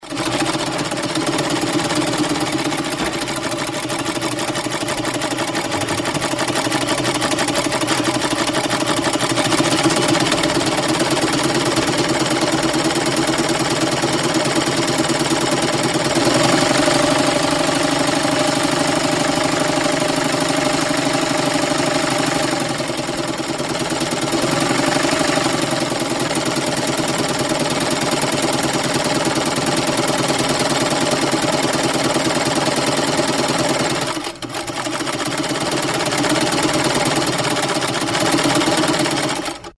Шум работающей швейной машины